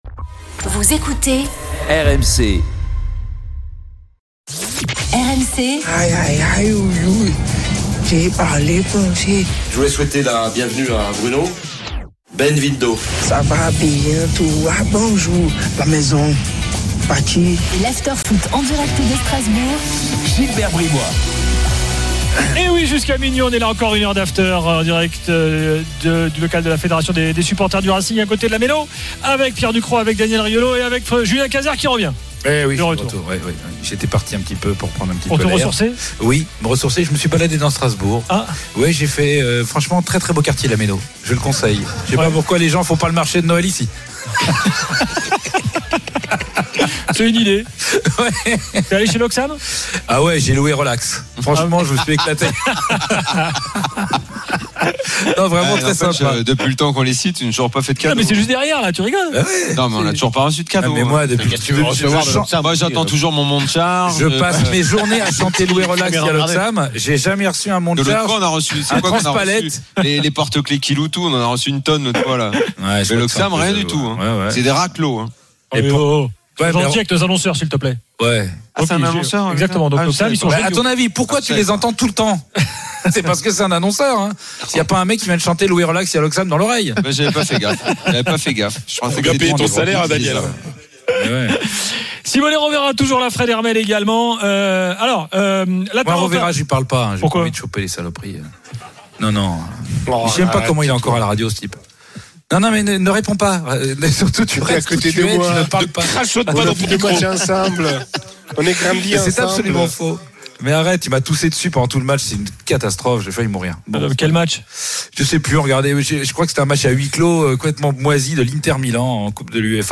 L'Afterfoot du 02 mars : En direct de Strasbourg – 23h/0h